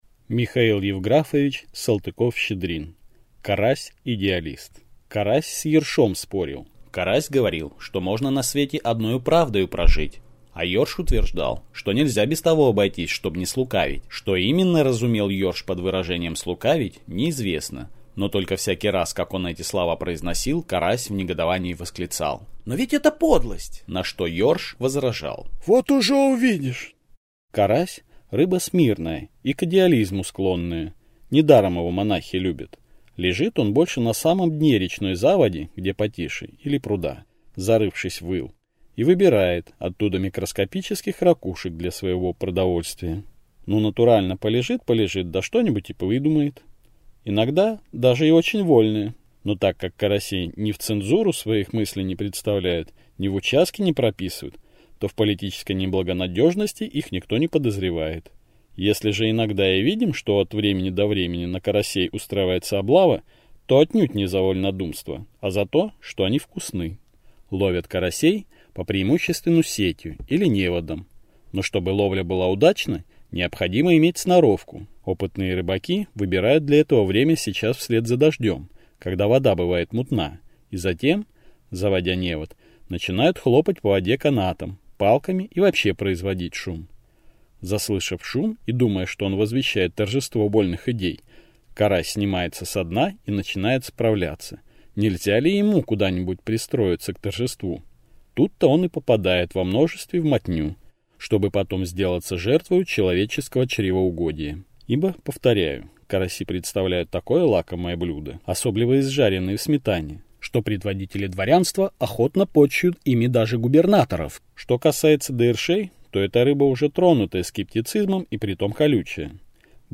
Аудиокнига Карась-идеалист | Библиотека аудиокниг